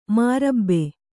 ♪ mārabbe